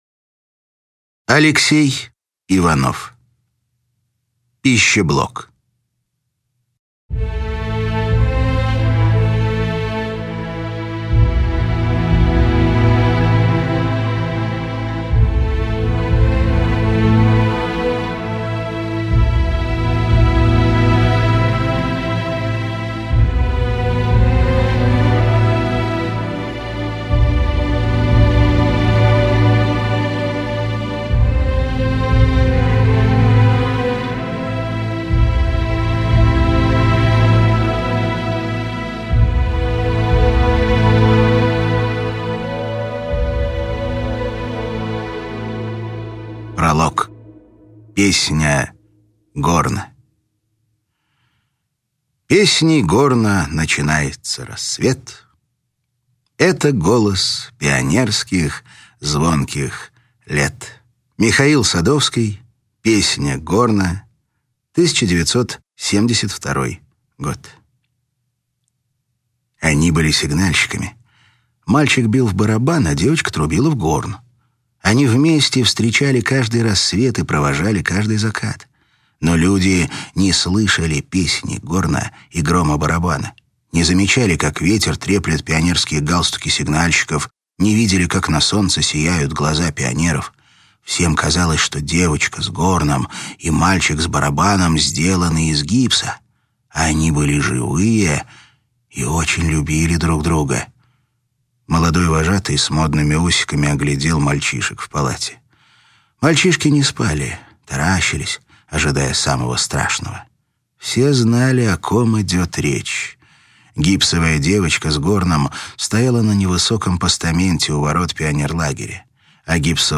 Аудиокнига «Пищеблок» в интернет-магазине КнигоПоиск ✅ в аудиоформате ✅ Скачать Пищеблок в mp3 или слушать онлайн